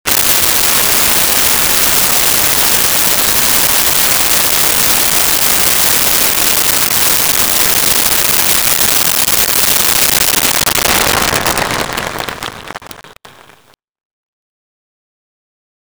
Concert Applause
Concert Applause.wav